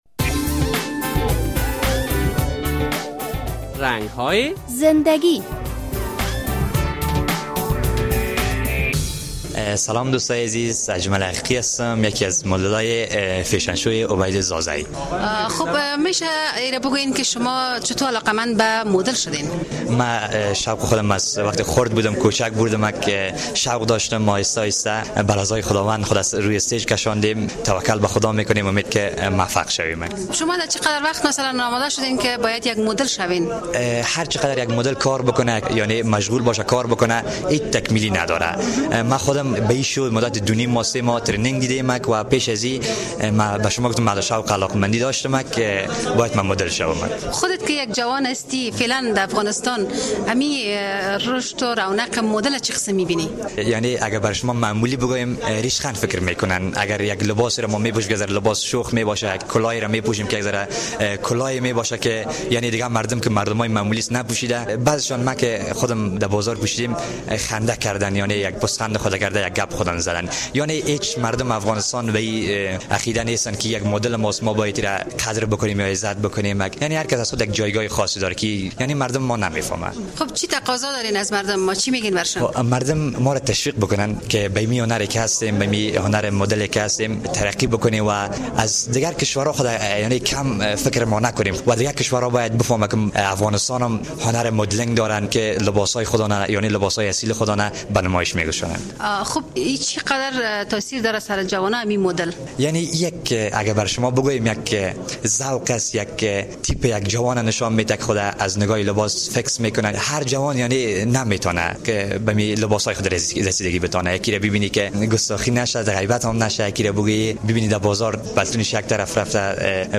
در این برنامهء رنگ های زنده گی با یک تن از مدل های افغان مصاحبه شده است.